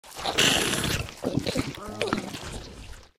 zombie_eat_0.ogg